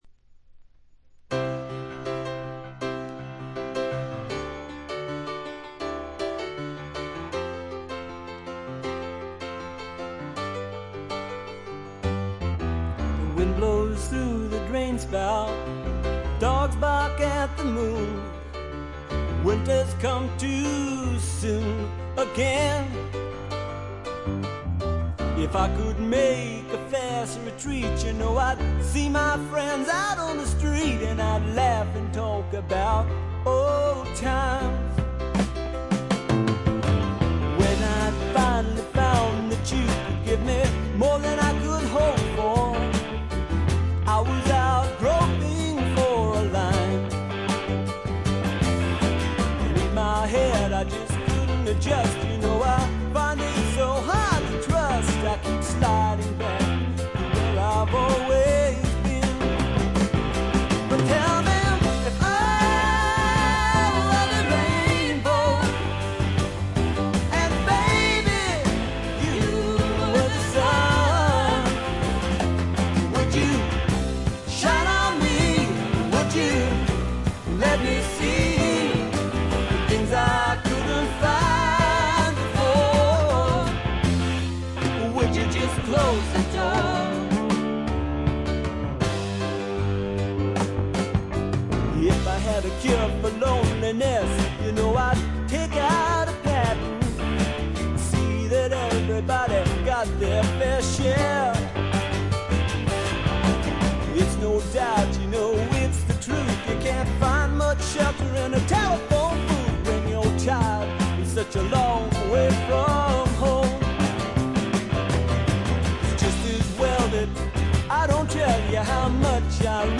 これ以外は軽いチリプチ少々、散発的なプツ音2-3回という程度で良好に鑑賞できると思います。
試聴曲は現品からの取り込み音源です。
Recorded at Larrabee Sound , Holywood , California